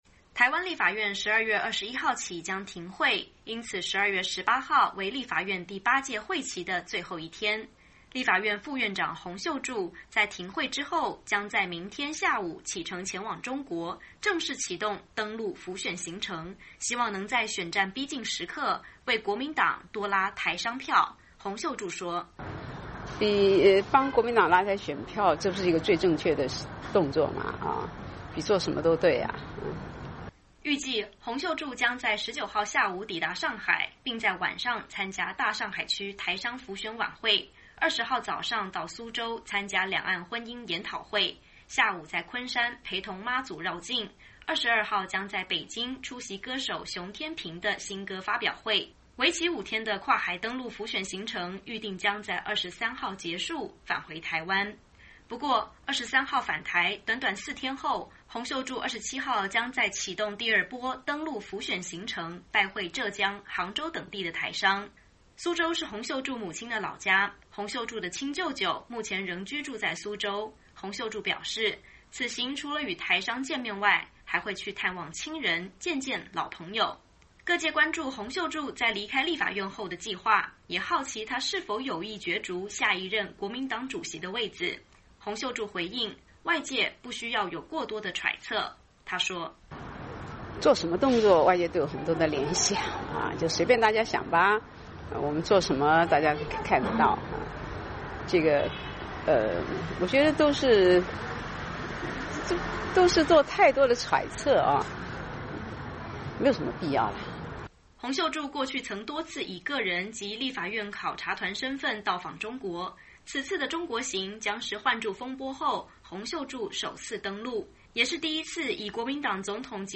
洪秀柱说：“做什麽动作外界都有很多的联想，就随便大家想吧！我们做什麽大家都看得到，我觉得这都是做太多的揣测，没有必要。 ”